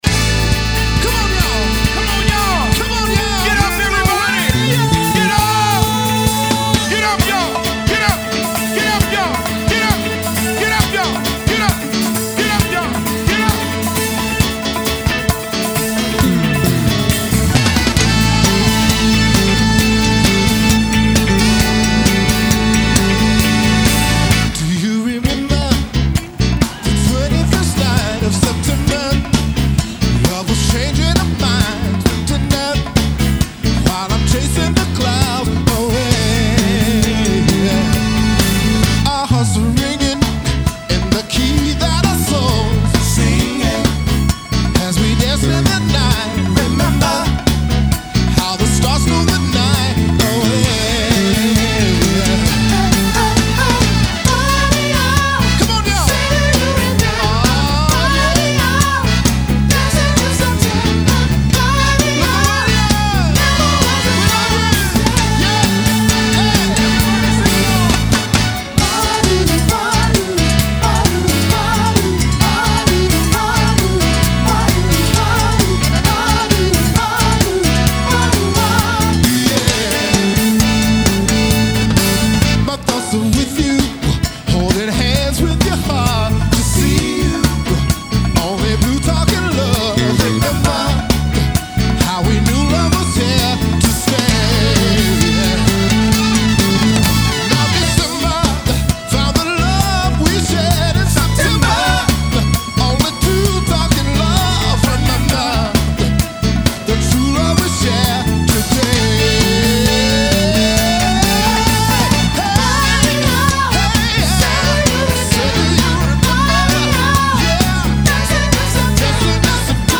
Jazz Funk